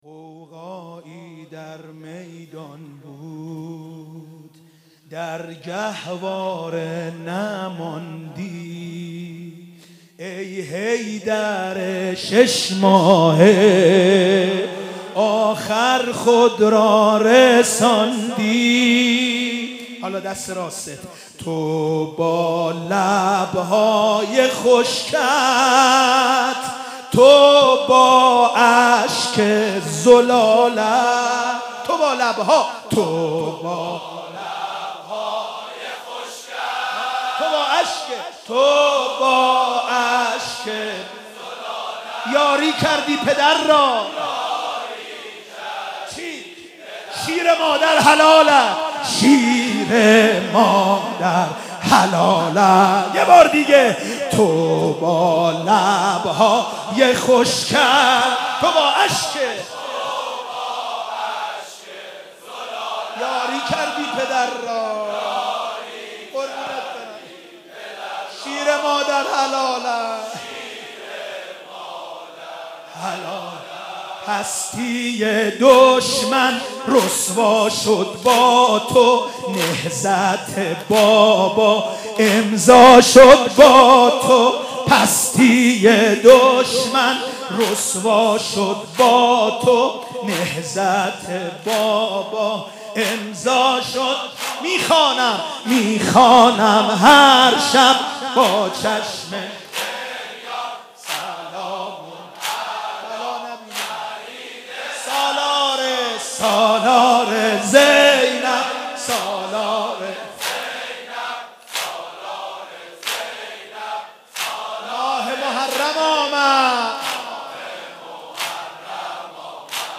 شب هفتم محرم95/هیئت رزمندگان اسلام قم
روضه حضرت علی اصغر(ع)